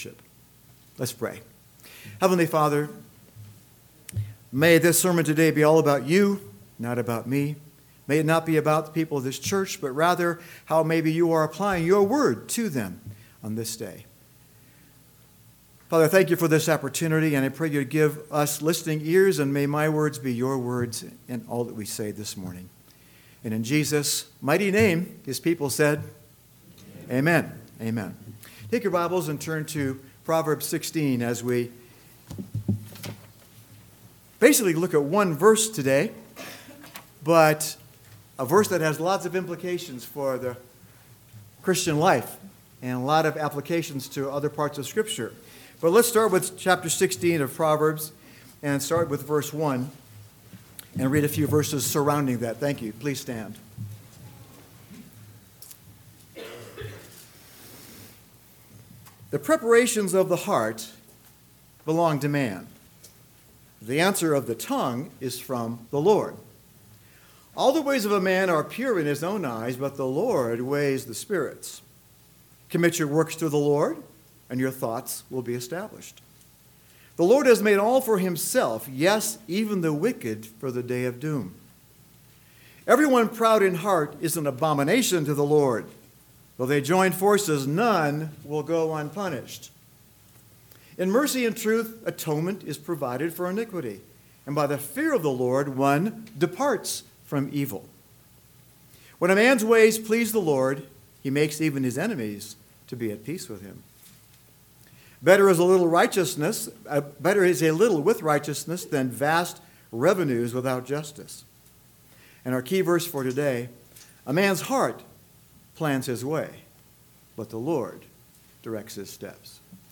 AM Sermon – 12/31/2023 – Proverbs 16:9 – Surprise!